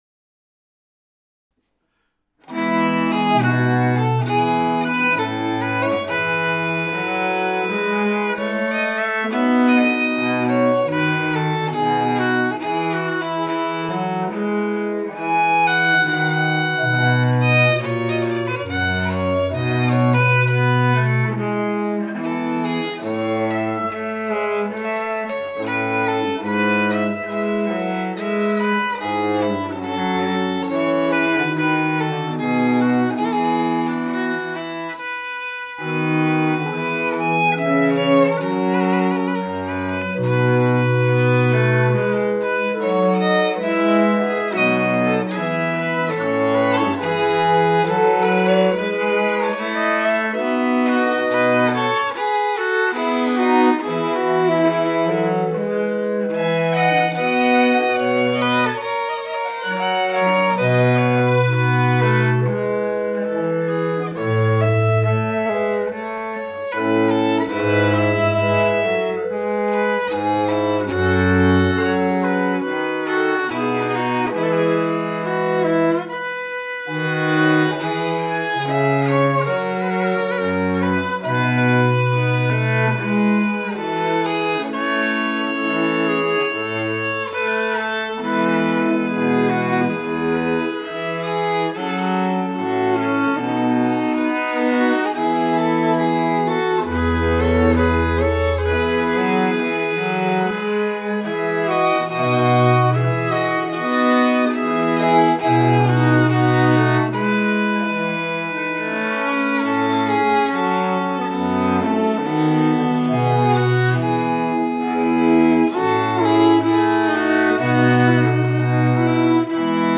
Here also are three examples of multi-track recording, with me playing all the instruments. A Trio Sonata movement by